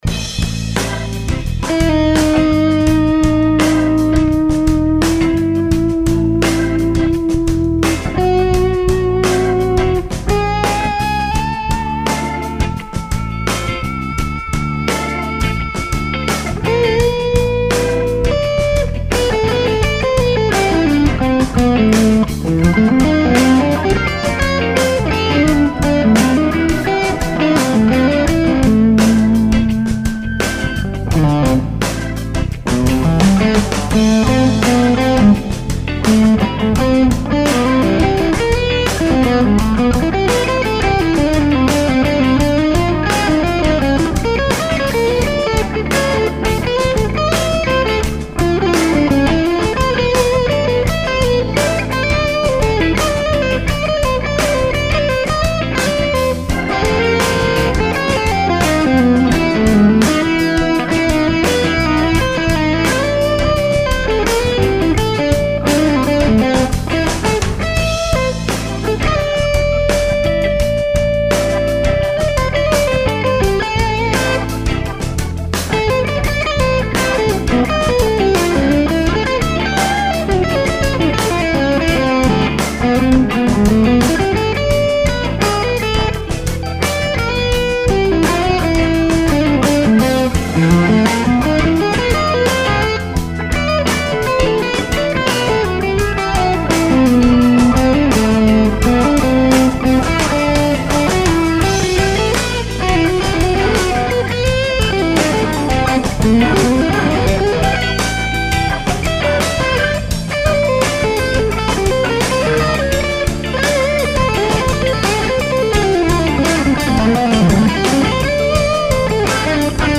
It is back in California now and heading to NAMM. 2X6L6, Bluesmaster HRM.
Cleans are a lot more Fender-like and the OD has a brassy quality to it.